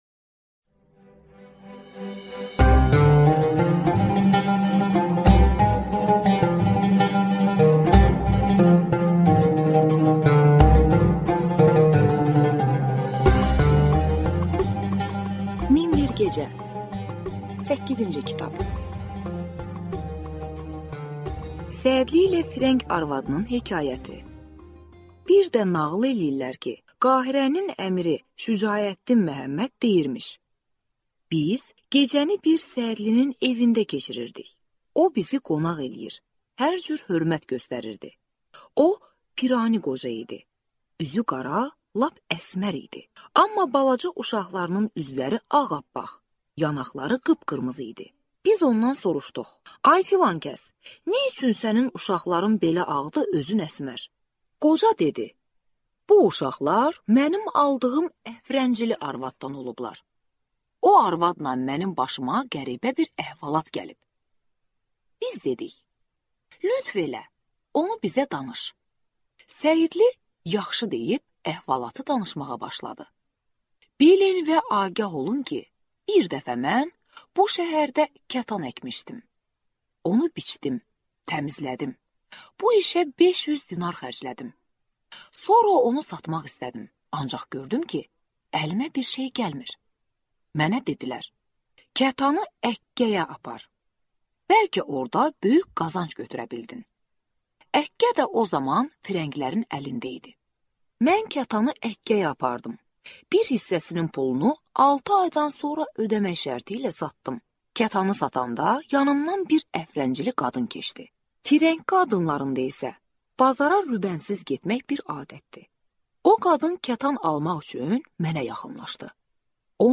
Аудиокнига Min bir gecə 8-ci cild | Библиотека аудиокниг